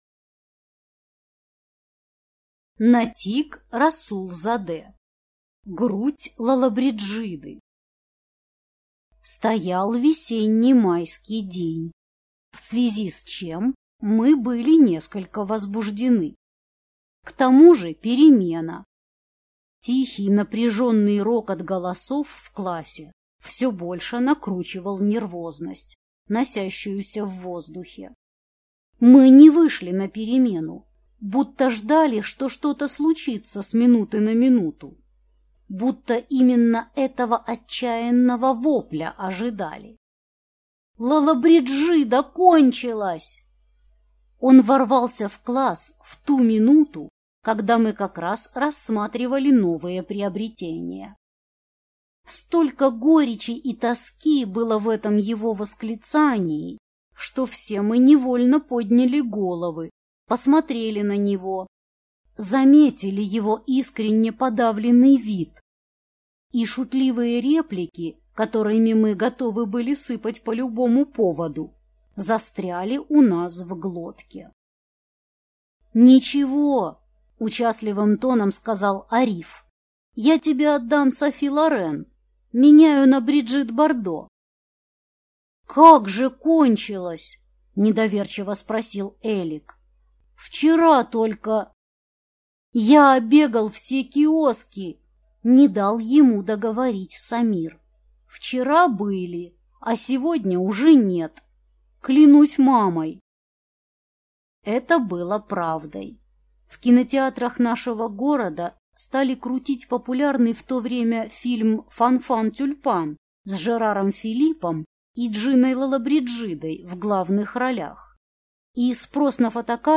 Аудиокнига Грудь Лоллобриджиды | Библиотека аудиокниг